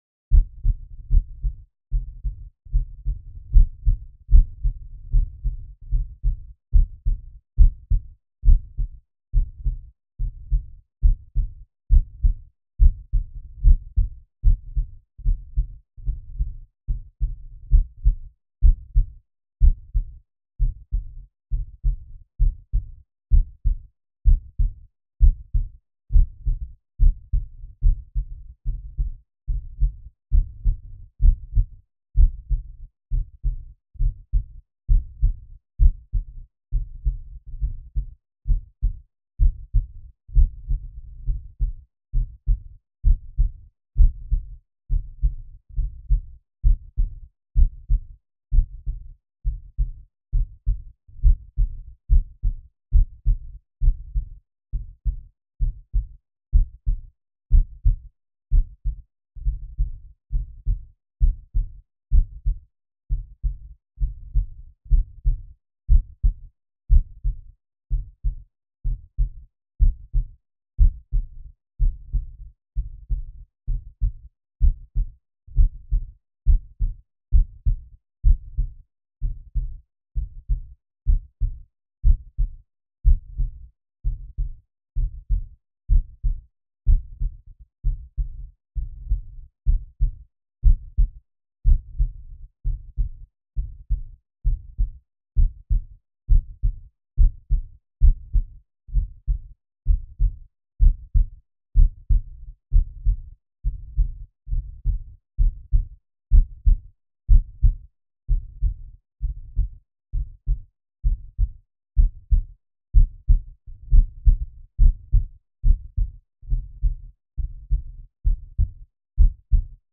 A simple DIY contact mic was the 1st thing that popped into my head.
I soldered up a piezo buzzer from rat shack & taped it to my chest.
The 312 worked wonderfully. It required all the gain I could give it but cycle buzz wasn’t an issue. Only the cheap piezos self noise was left to deal with.